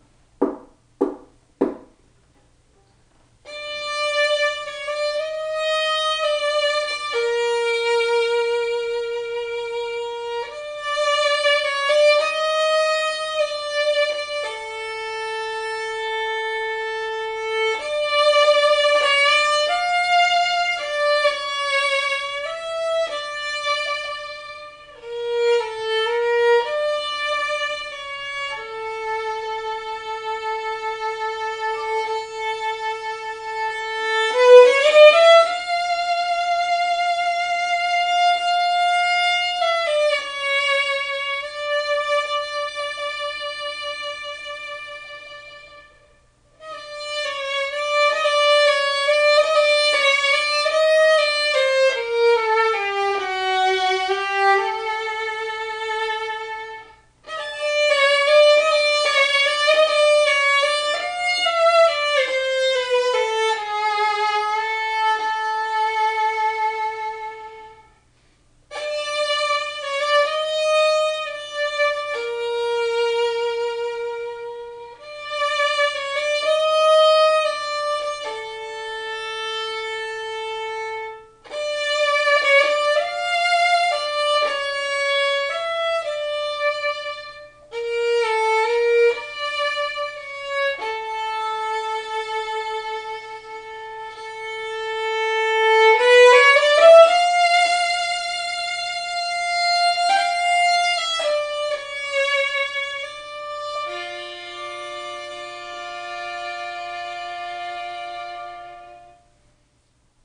The acoustics here in our living room are exceptional, and really help to 'hear' accurately.
Click the four violins below: to hear real samples of "Rich" Tuned violins...